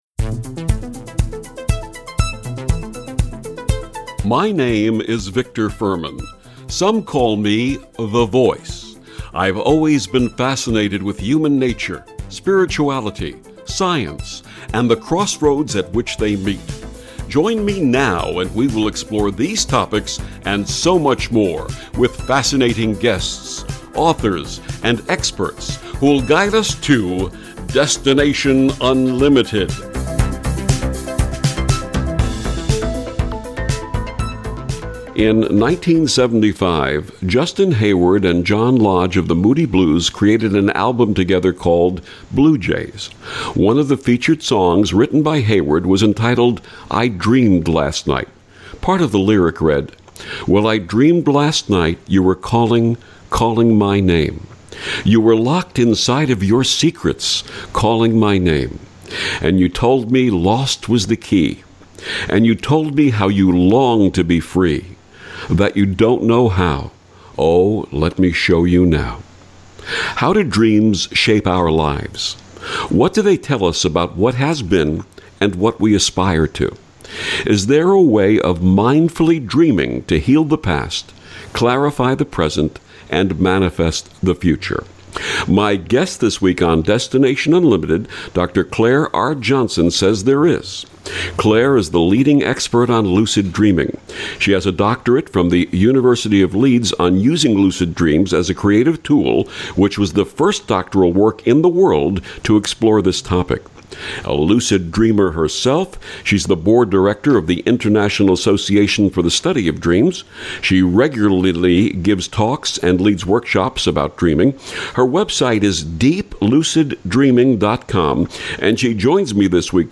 Lucid Interview – listen here